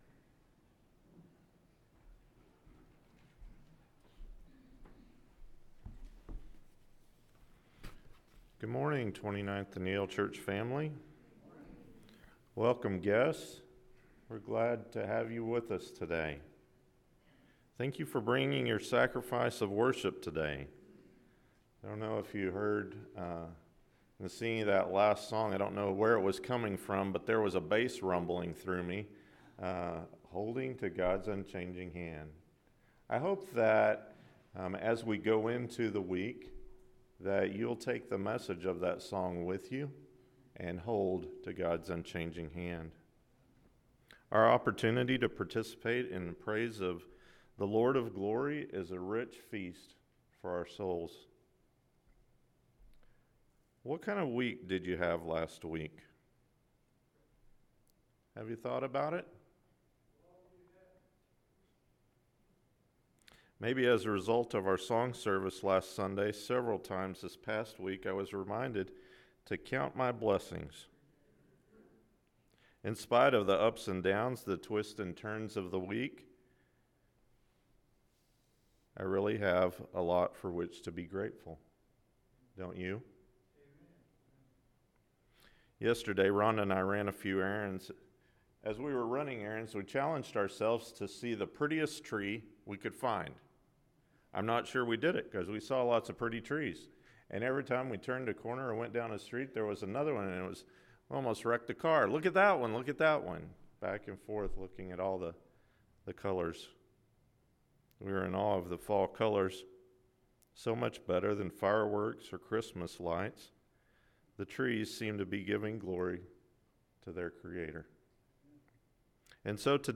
Kingdom Stories: The Talents & The Minas Sermon – Matthew 25:14-30, Luke 19:11-27 – Sermon
KingdomStories-TheTalentsAndTheMinasSermonAM.mp3